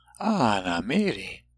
When paragraphs of Láadan are given, sound files will be provided for the entire paragraph as well as each sentence.